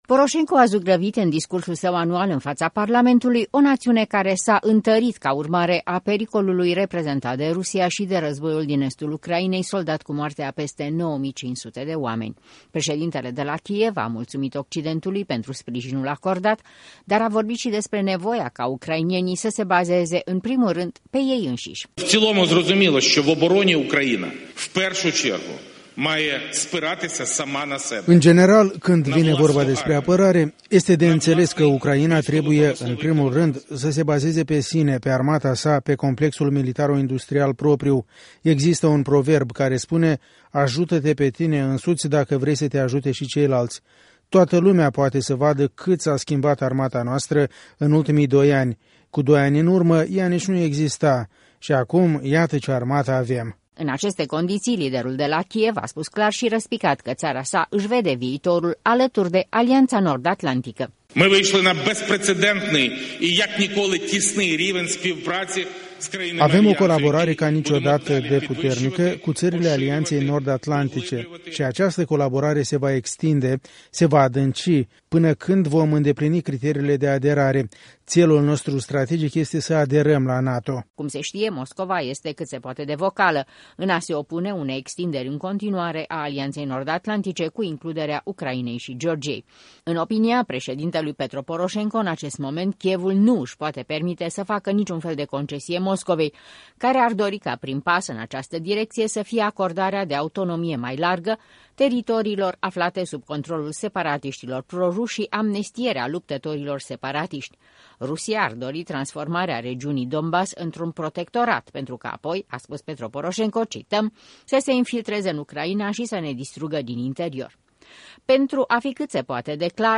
Poroşenko a zugrăvit în discursul sau anual în faţa Parlamentului o naţiune care s-a întărit ca urmare a pericolului reprezentat de Rusia şi de războiul din estul Ucrainei soldat cu moartea a peste 9500 de oameni.
Astăzi în Parlamentul de la Kiev